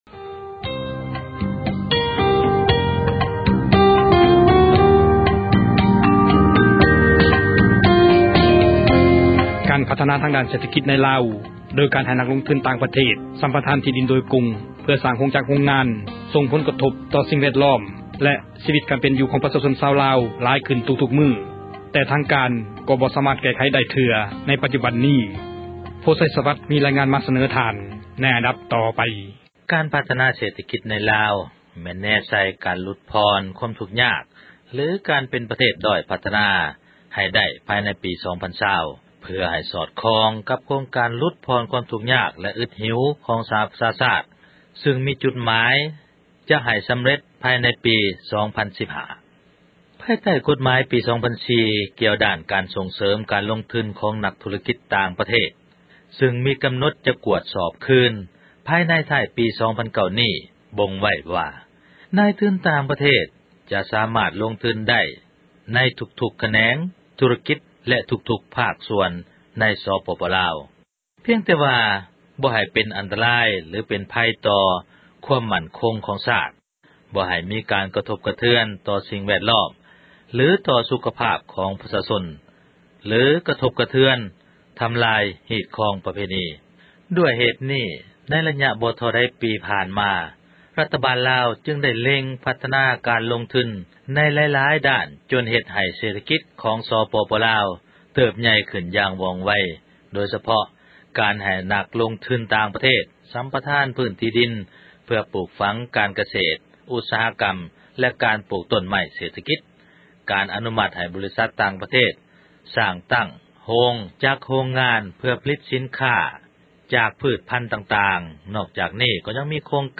ການພັທນາ ເສຖກິດ ໃນ ສປປລ – ຂ່າວລາວ ວິທຍຸເອເຊັຽເສຣີ ພາສາລາວ